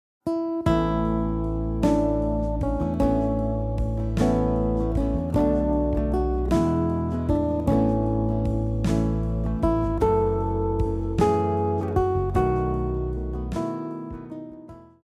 🎵 Melody with chords backing track
🎵 Full guitar arrangement backing track